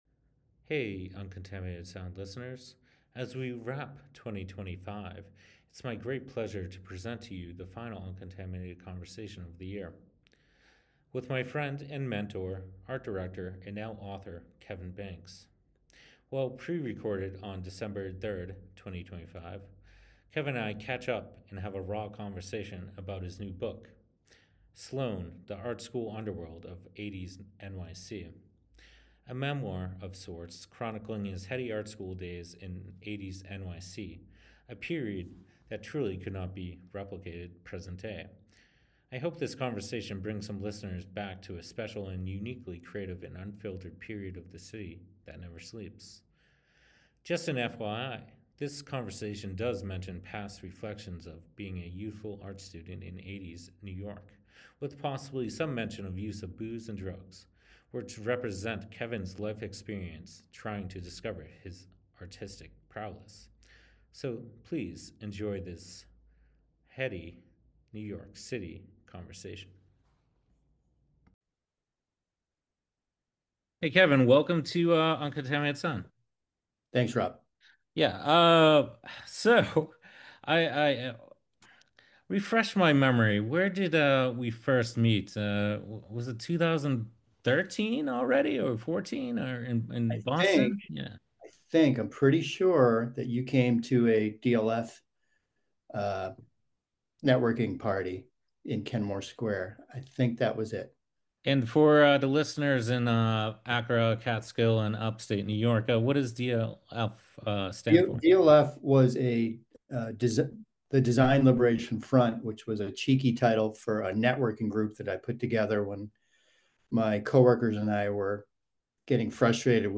These features offer listeners real, raw, and authentic conversations.